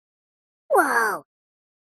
Звуки мультяшные